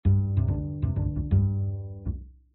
直立爵士低音提琴05
描述：爵士直立式贝司的变体01
Tag: 爵士乐